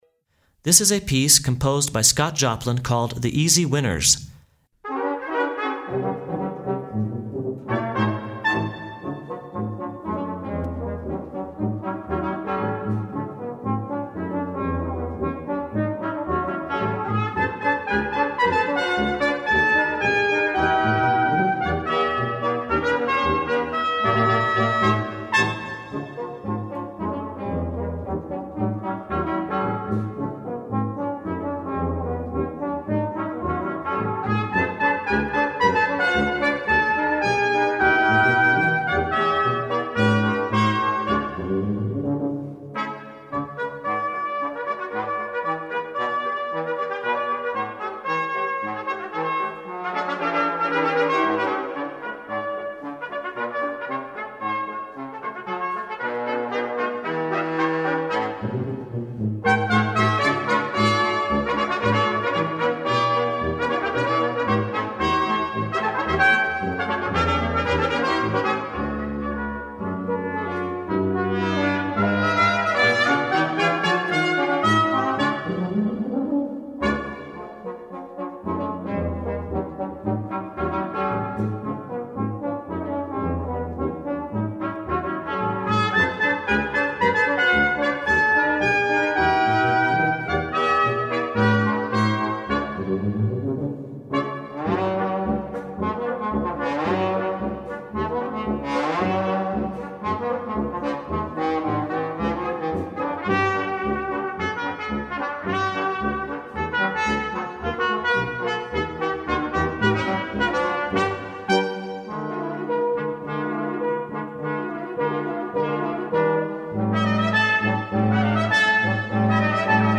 Denver brass quintet
The Peak Brass Quintet performs a wide repertoire of classical music.